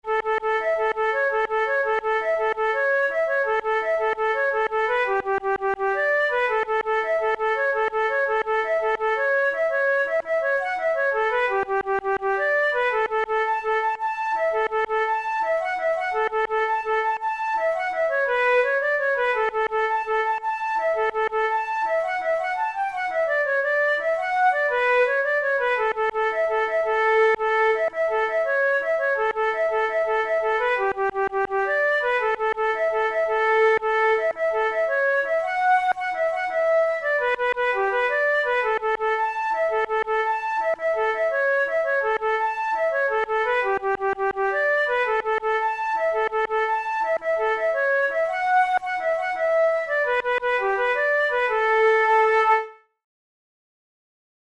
InstrumentationFlute solo
KeyA major
Time signature9/8
Tempo112 BPM
Celtic Music, Contemporary, Jigs